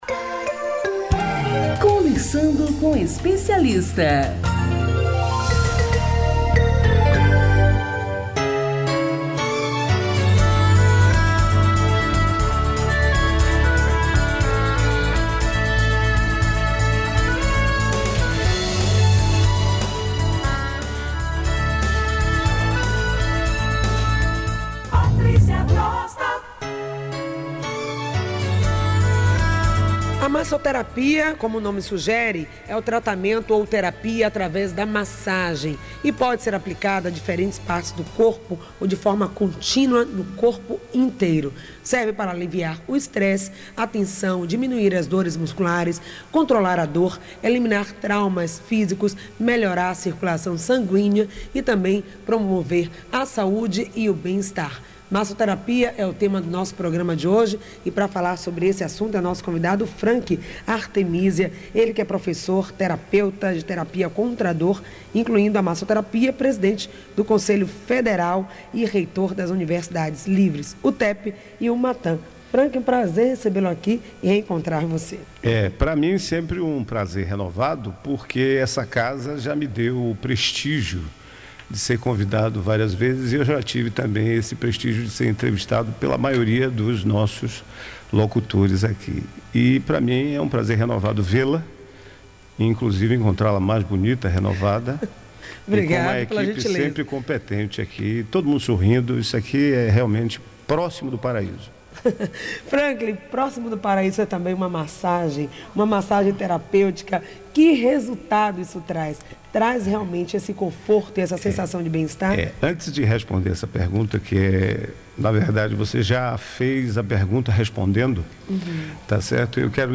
Ouça na íntegra a entrevista!